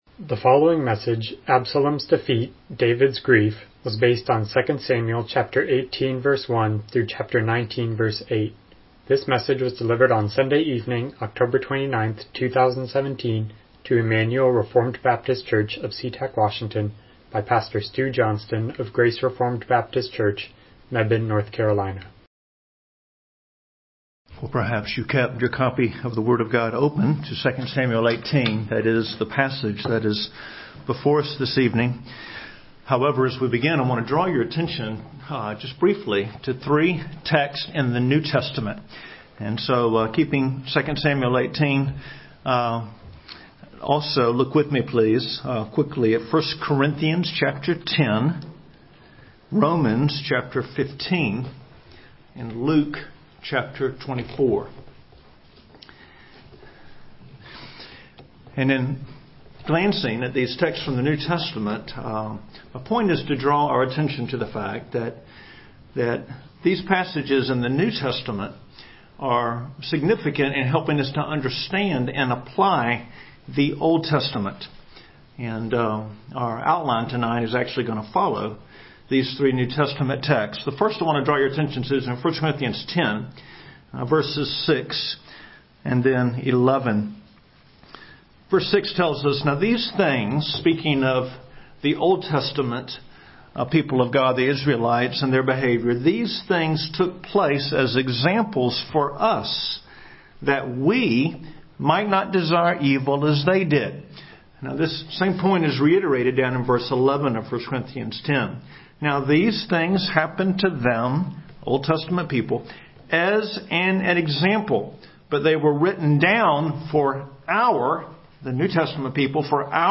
Passage: 2 Samuel 18:1-19:8 Service Type: Evening Worship « Absalom Rebels